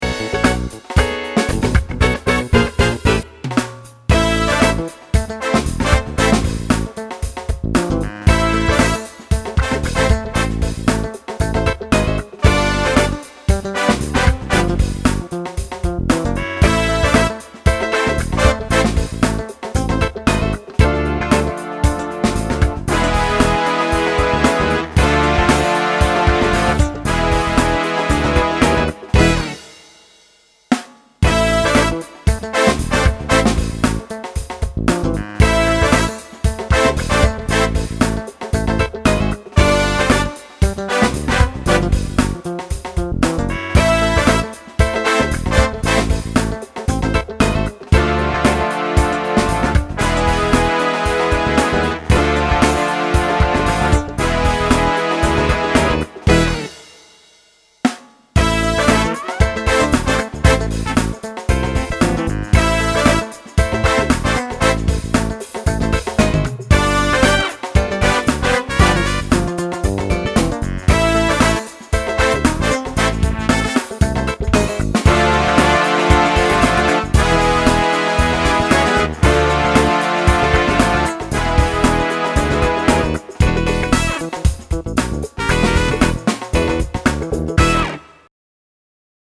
Лёгкий фанк
Всё на Korg
Just_Funk.mp3